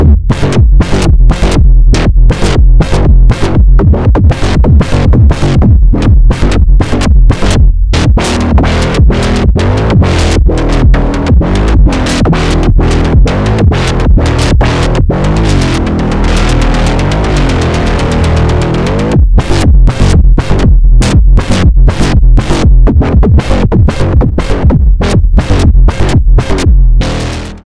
Analog Heat +FX with nothing connected at the inputs. Just sending program changes.